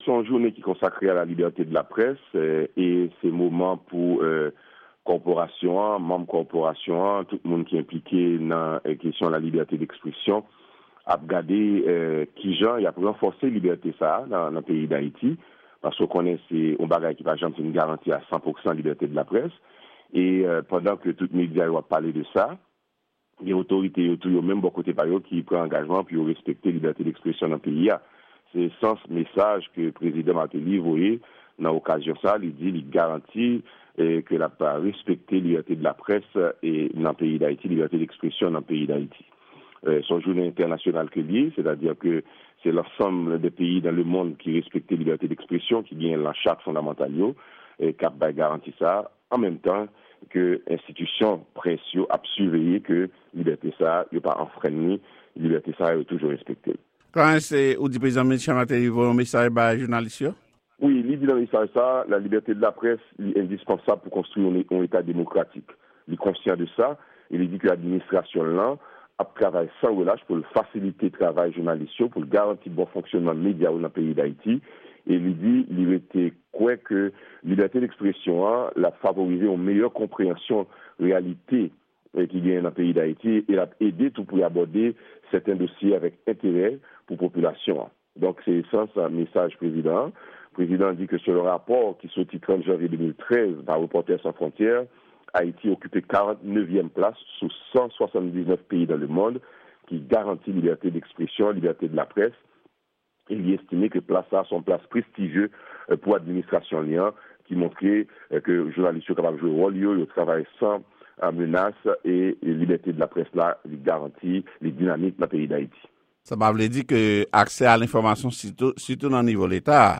Repòtaj